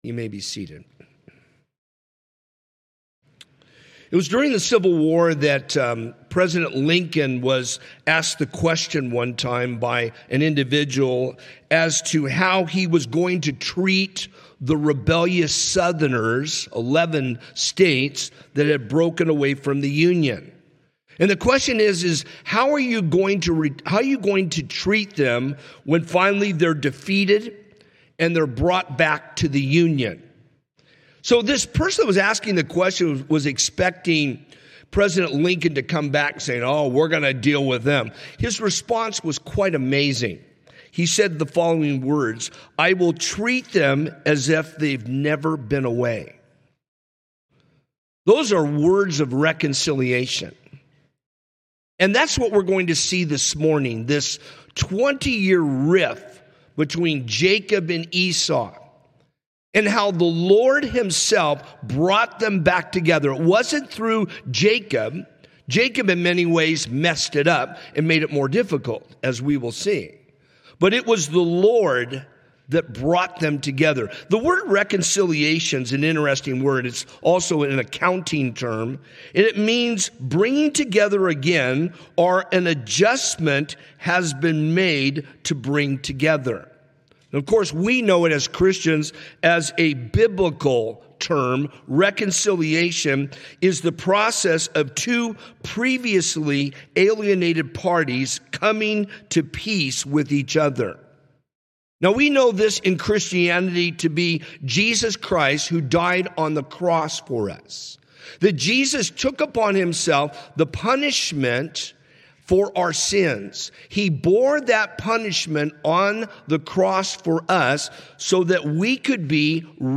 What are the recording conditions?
Sunday Morning - 10:30